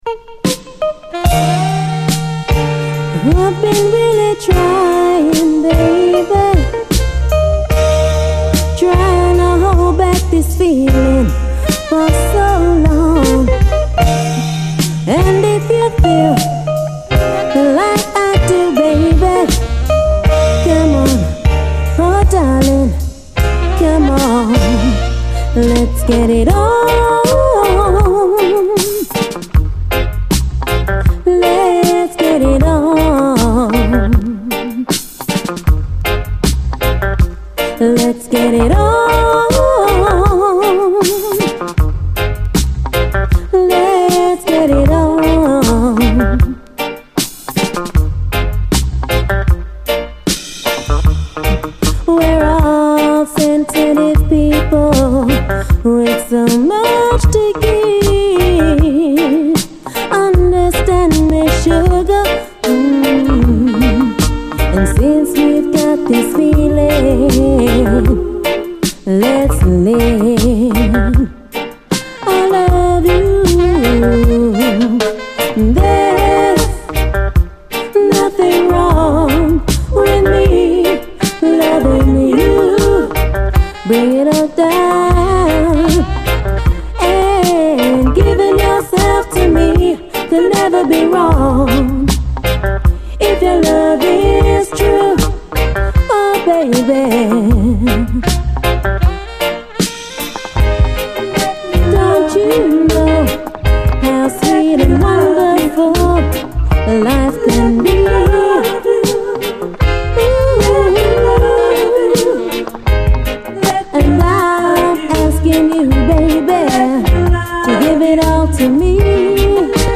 痺れ上がるほどにカッコいい内容のUKメロウ・シンセ・ソウル集です！
スカスカとチープなようでいて真っ黒く鬼メロウ、都会の闇に溶ける深いシンセの響きにヤラれます。